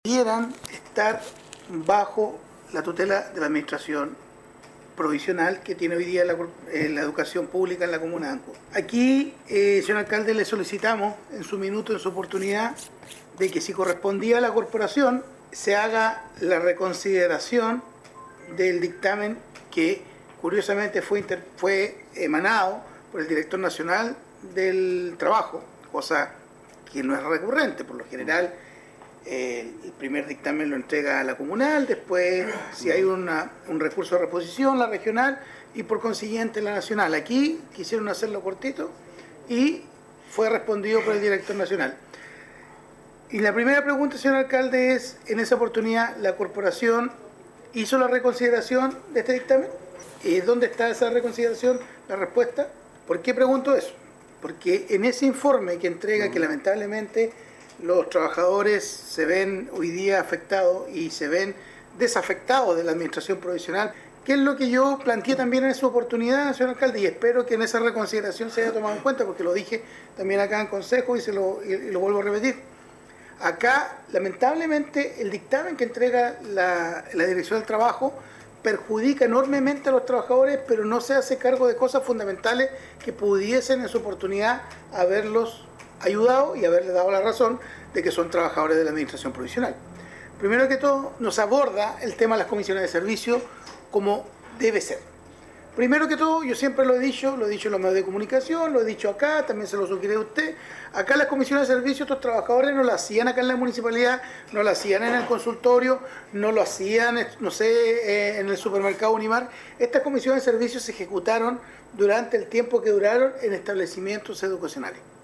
Asimismo, el concejal Andrés Ibáñez expresó la urgencia de realizar las gestiones ante los organismos competentes para encontrar una vía de salida a este conflicto.
Todos estos alcances sobre este problema por el que atraviesan los 29 funcionarios no reconocidos por la corporación municipal fueron tratados en la sesión de concejo realizada este lunes en Ancud y que correspondió al tercer llamado para constituir la reunión, luego que en las dos anteriores, no hubo Quorum para iniciarla.